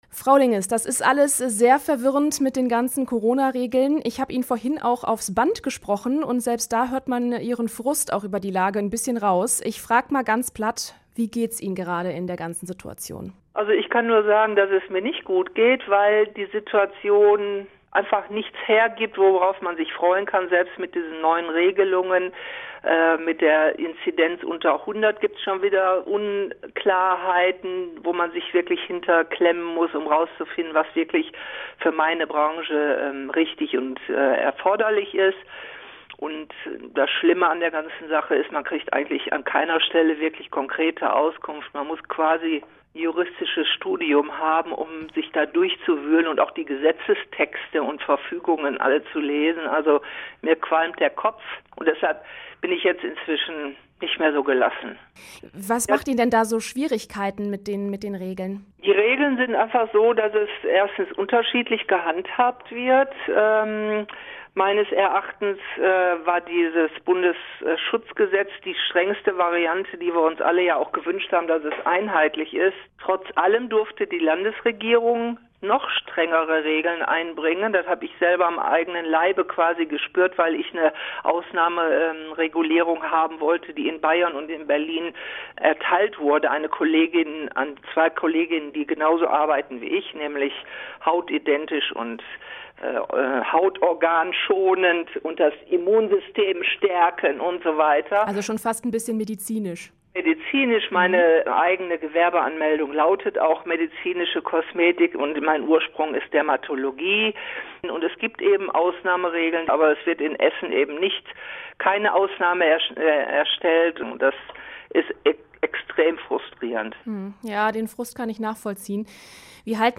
int-kosmetikerin.mp3